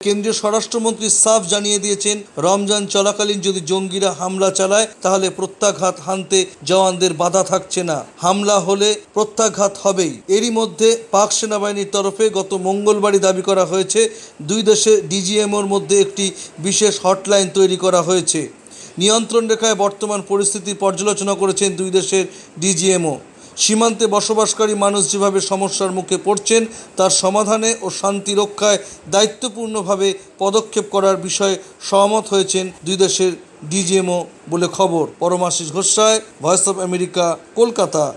প্রতিবেদন